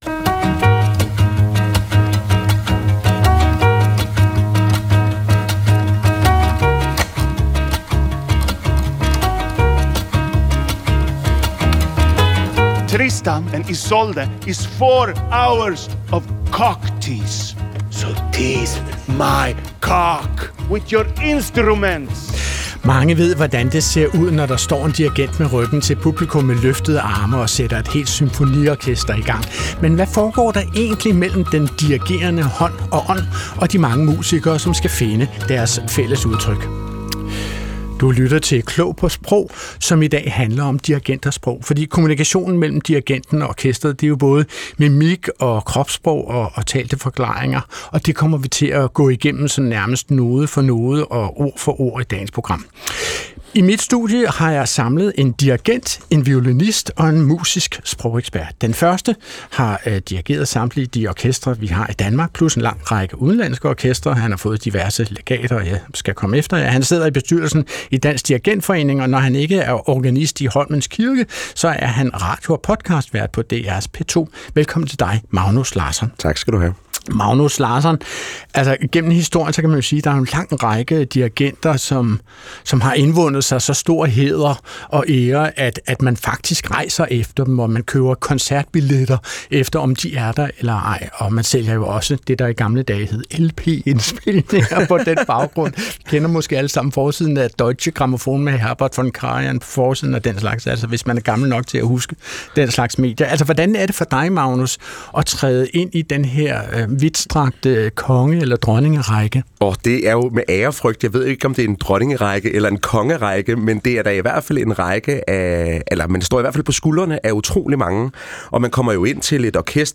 Klog på Sprog er programmet, der interesserer sig for, leger med og endevender det sprog, vi alle sammen taler til daglig. Adrian Hughes er værten, der sammen med et veloplagt panel er helt vild med dansk.